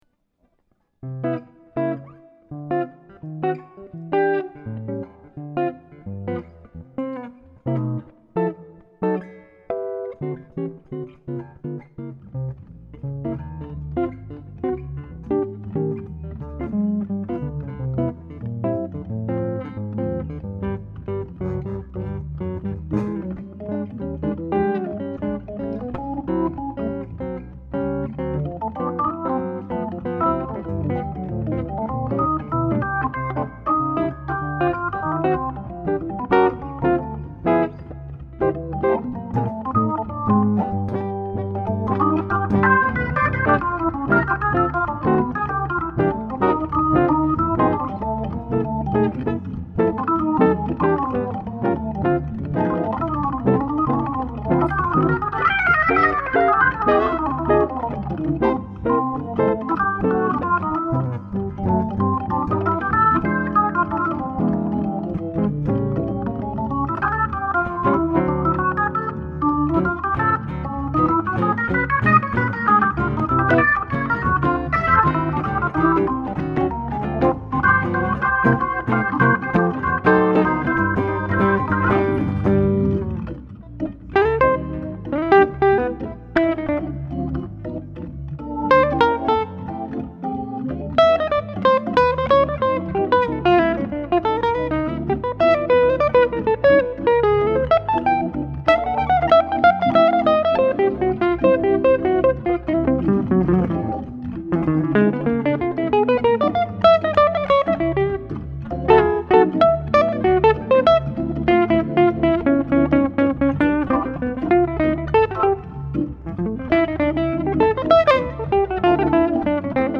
proche manouche
l'orgue
la guitare
l'anatole est filmé...c'est un des exemples d'accompagnement guitare orgue et réciproque....les midifiles seront fournis avec la video...pour le moment je ne vous mets qu'un bout d'audio.
Pour le DVD c'est plus decontract et en fait.....c'est plus chaleureux....rien à prouver...juste essayer de transmettre qq trucs à des amis organistes.....il n'y a que des premieres prises....voilà comme on devrait toujours faire mais.....on est tellement attendus par certains journalistes tueurs....qu'on fait gaffe et la musique s'en ressent...La on est peinard, à la maison....sans risques....sans pression....une tite clope....unchti cassis eau fraiche....une bonne rigolade....on bosse pour des gens qui ont envie d'apprendre.....pas de chercher la petite bete.....c'est chouette ainsi.